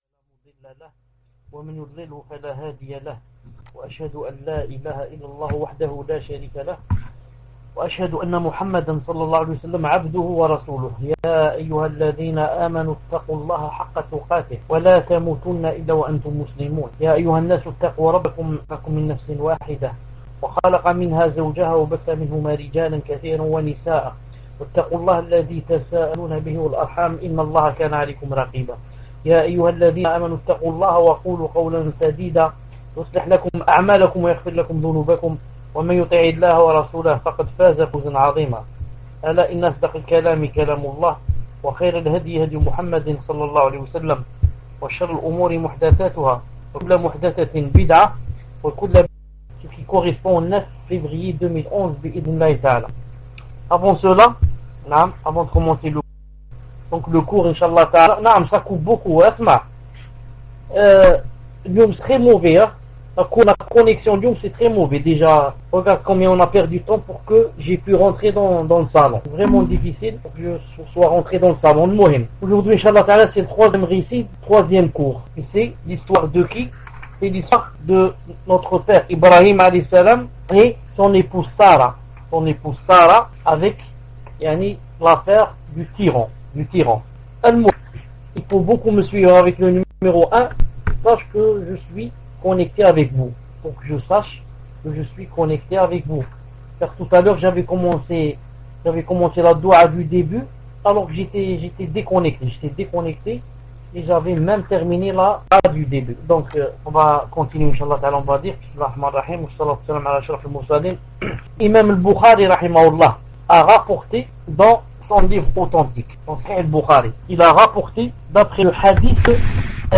Mercredi 05 Rabi' Al-Awwal 1432 - 09 février 2011 | 3éme cours - Ibrahim (paix et salut sur lui) et son épouse Sarah Durée : 1h 43 min